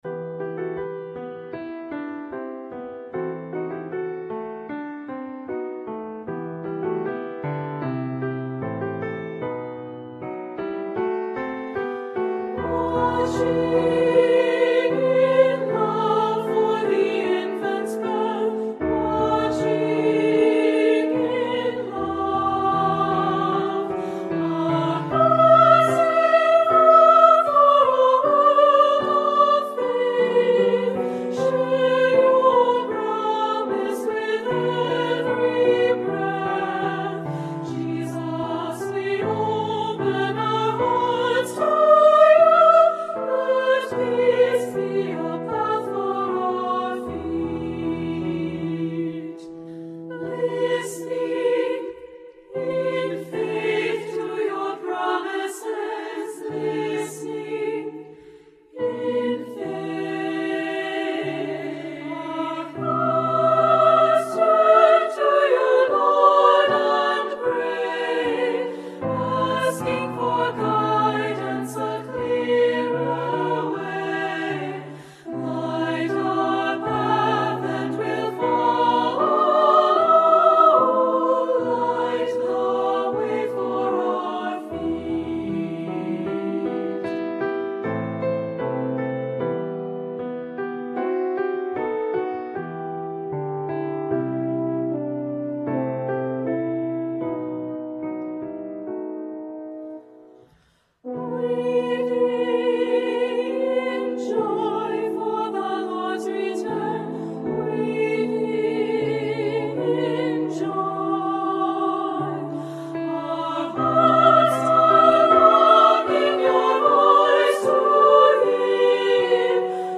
Voicing: Soprano Solo, SSAA (Children or Women)